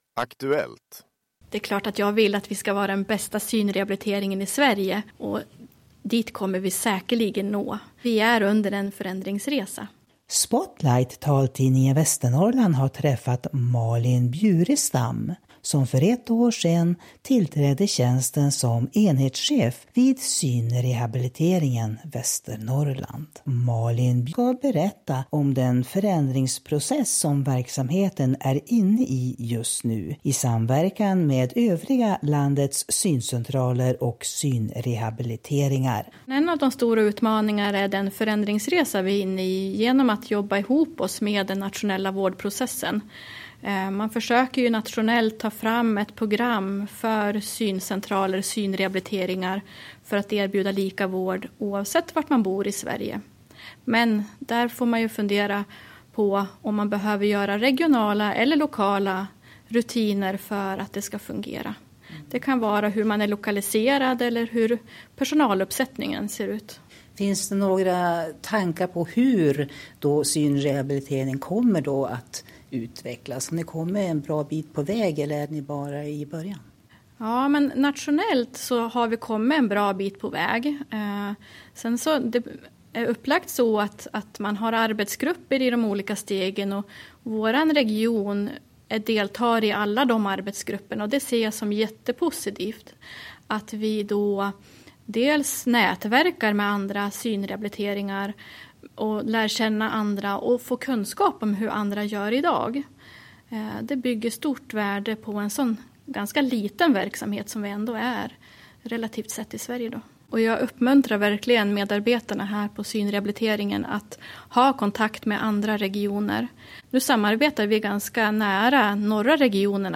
Häng med in i operationssalen!